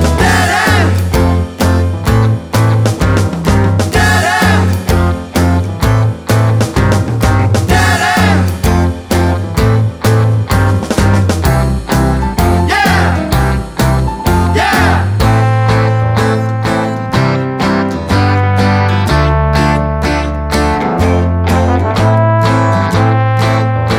Pop (1960s)